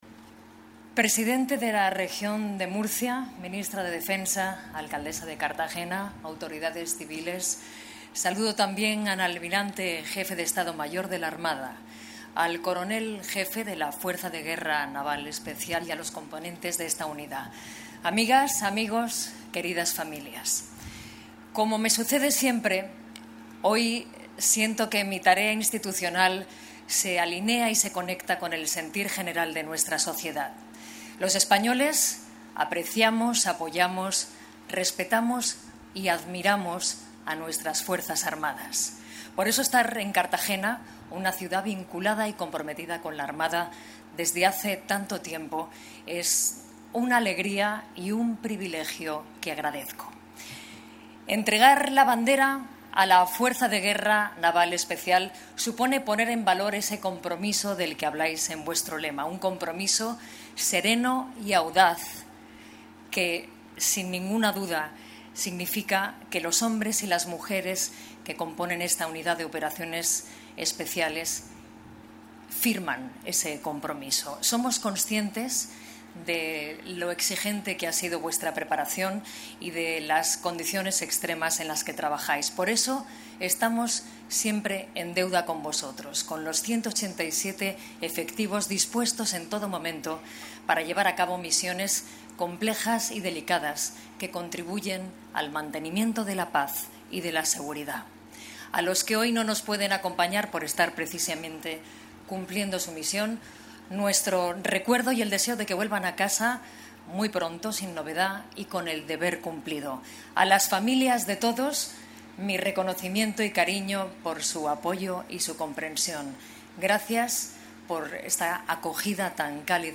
Audio: Declaraciones Noelia Arroyo (MP3 - 1,33 MB)